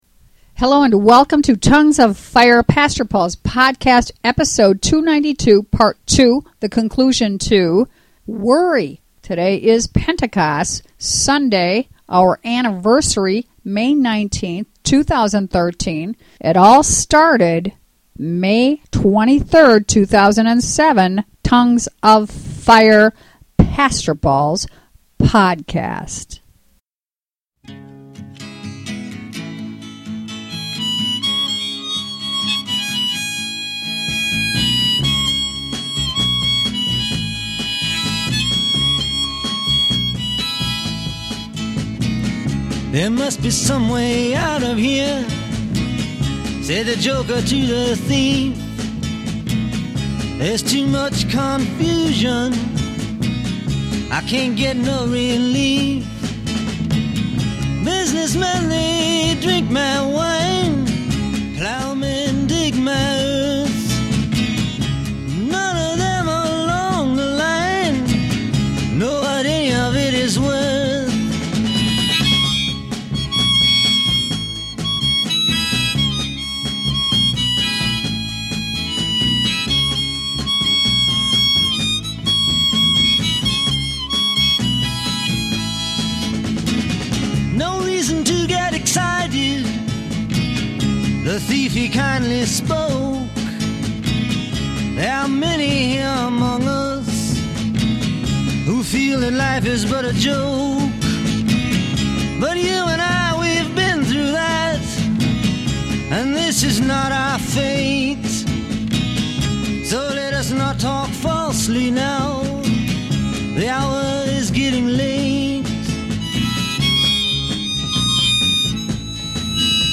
Originally Recorded August 8, 1987